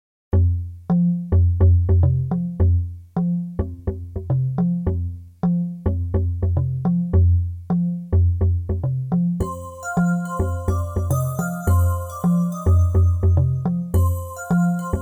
Cinematic, Instrumental